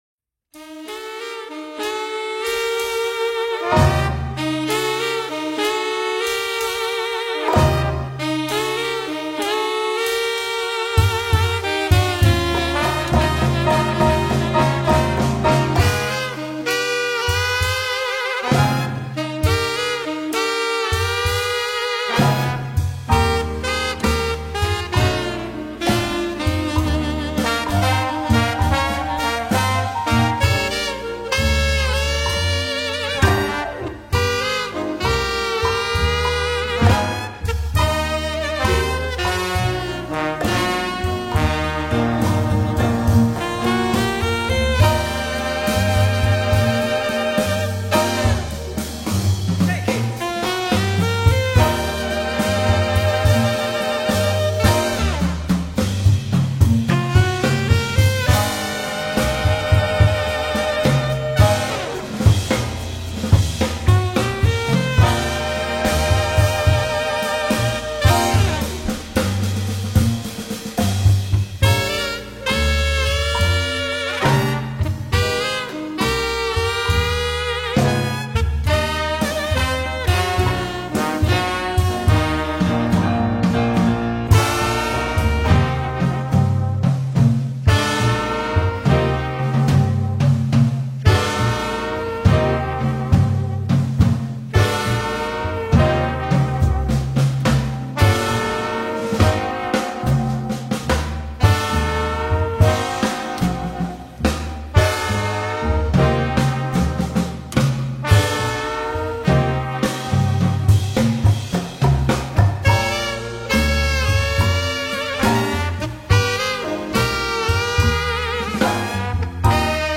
Burlesque-Music.mp3